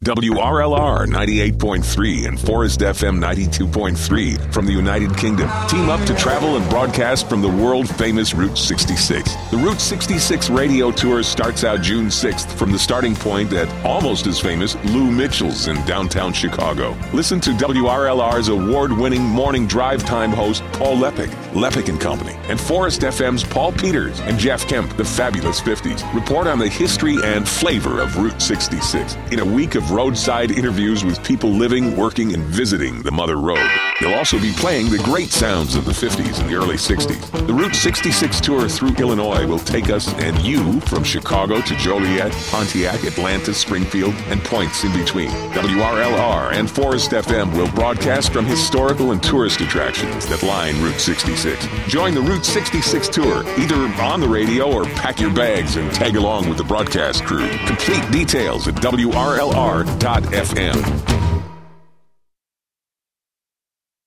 Here’s a promo for the Route 66 Radio Tour:
WRLR-Route-66-Promo.mp3